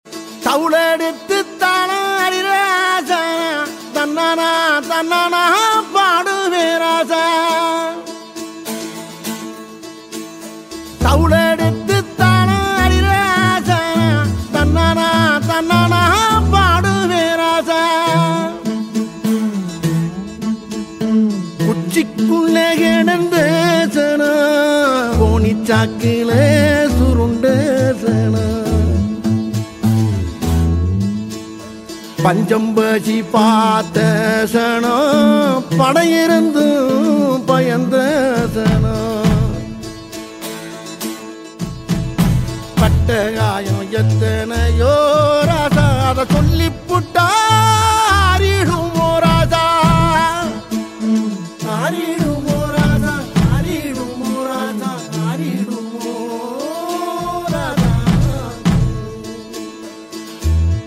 heartfelt rendition